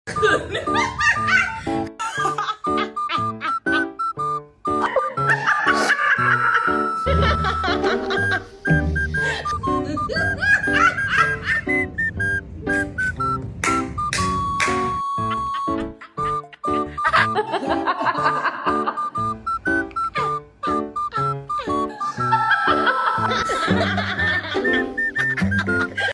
Risada